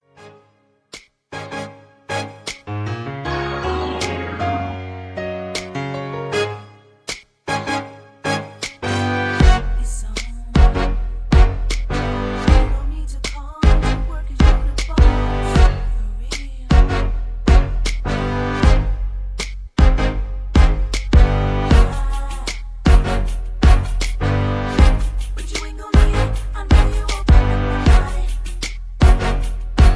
(Key-Db) Karaoke MP3 Backing Tracks
Just Plain & Simply "GREAT MUSIC" (No Lyrics).